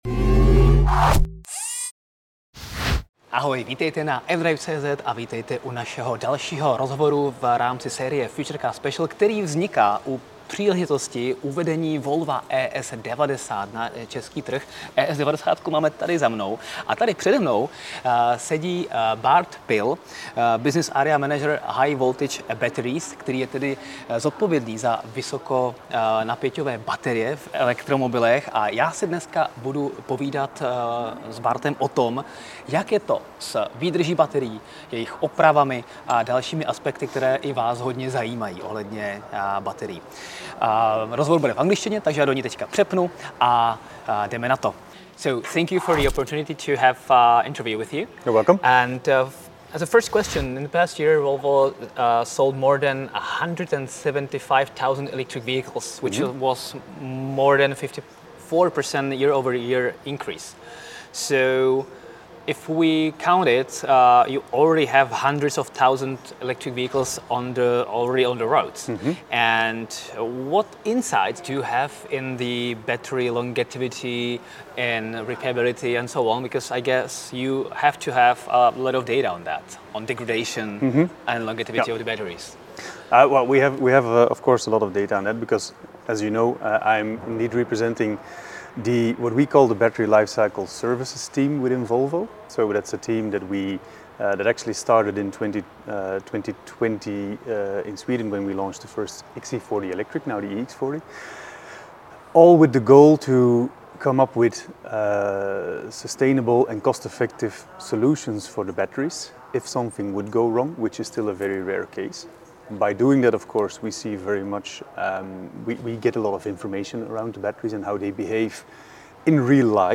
Tématem rozhovoru byla reálná životnost baterií v elektromobilech Volvo, jejich opravitelnost, logistika bateriových center i dlouhodobá udržitelnost celého systému. Celý rozhovor si můžete pustit ve vloženém videu níže.